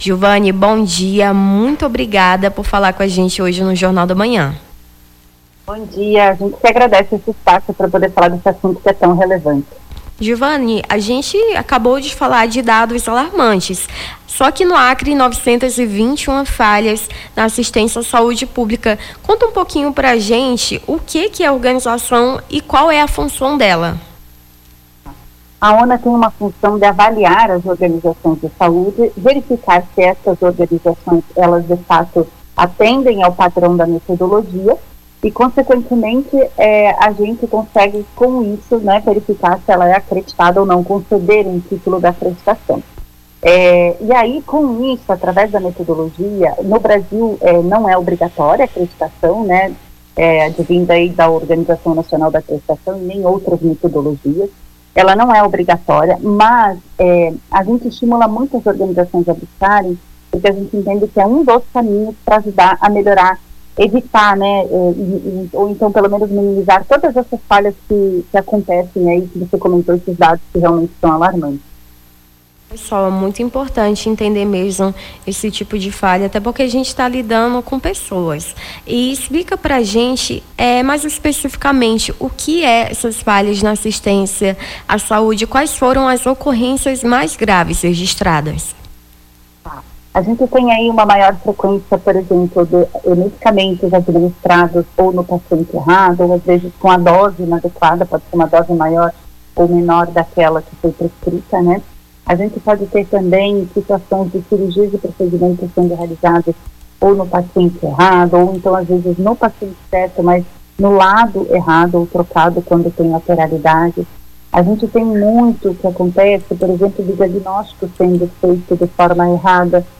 Nome do Artista - CENSURA - ENTREVISTA (ASSISTENCIA A SAUDE ACRE) 18-04-25.mp3